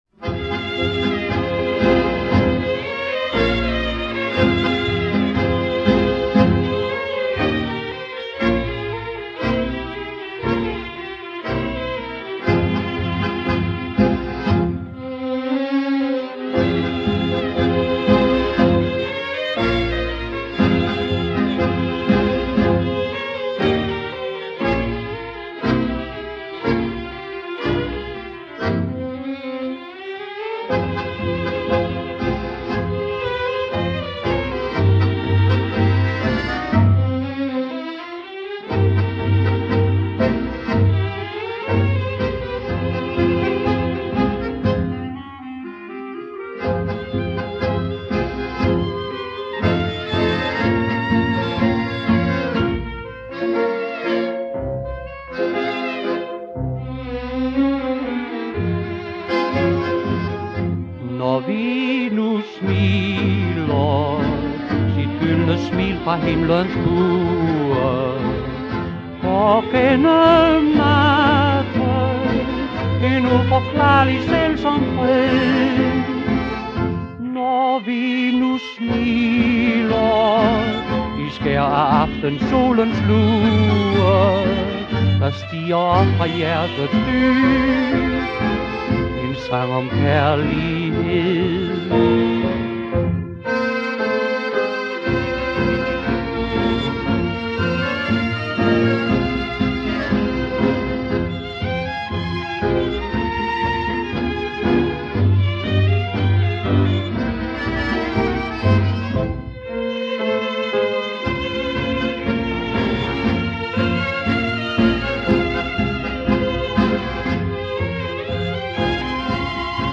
vocal
Чудесное танго!! angry